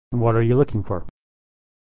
Unstressed 'for' is reduced = /fər/